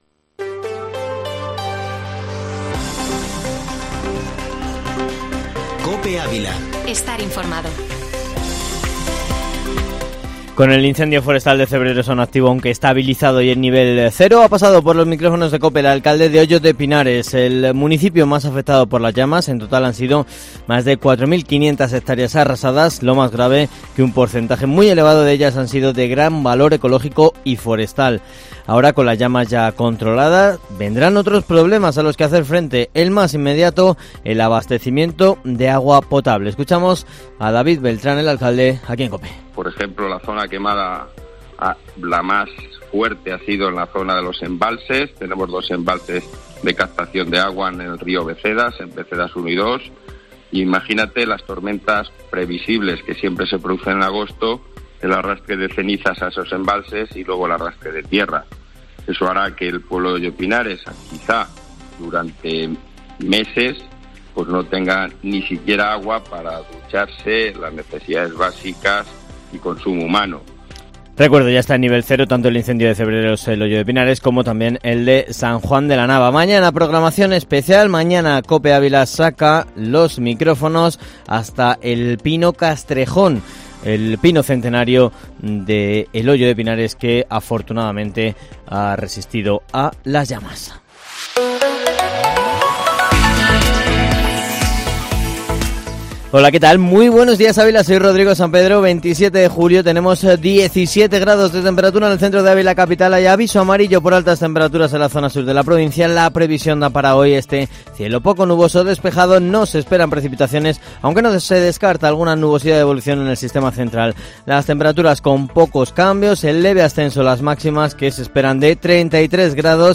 Informativo Matinal Herrera en COPE Ávila -27-julio